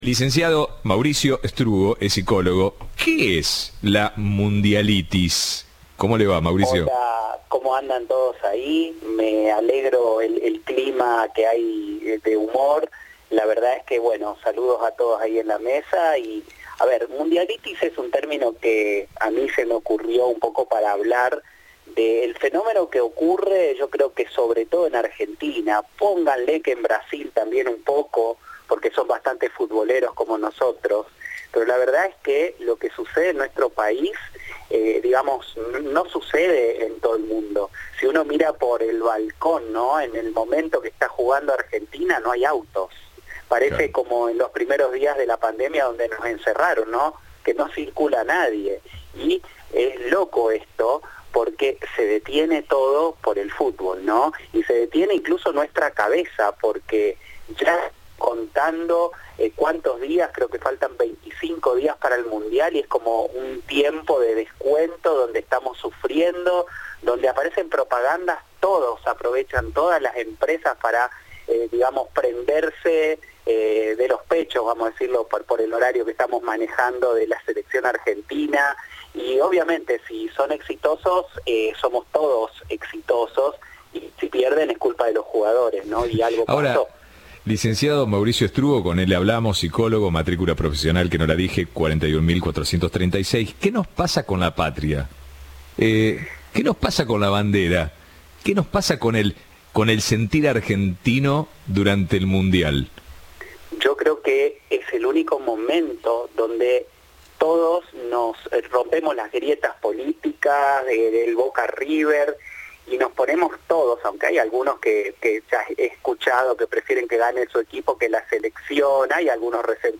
Entrevista de Rodolfo Barili.